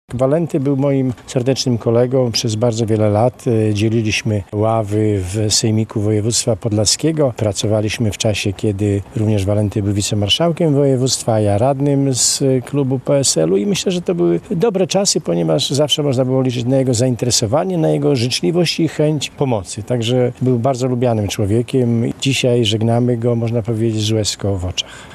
Był życzliwy, zawsze gotów do pomocy i pełen zaangażowania - tak zmarłego wójta gminy Bielsk Podlaski wspomina przewodniczący sejmiku woj. podlaskiego Cezary Cieślukowski.